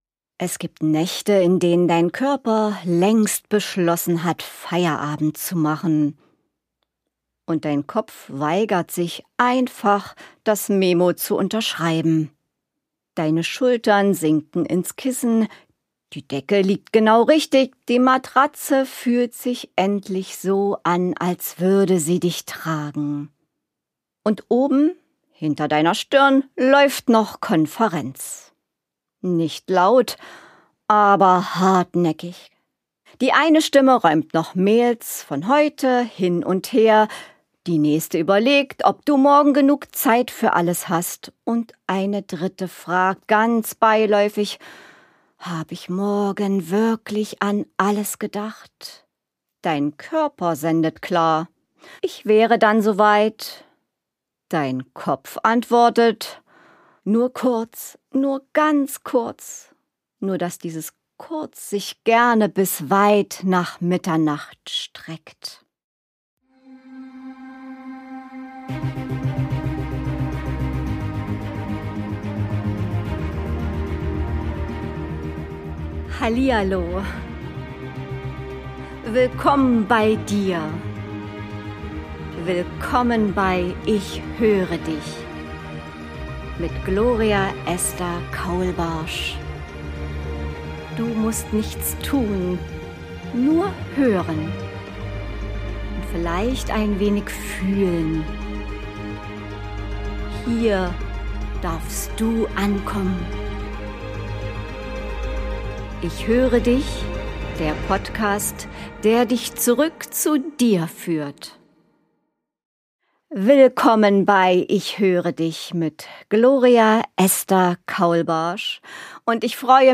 In dieser Folge nehme ich dich mit in genau so eine Nacht: in die kleinen Sätze, die man sich sagt, und in die Stille dazwischen. Eine Geschichte aus dem echten Leben - klar erzählt, war, ohne Eile.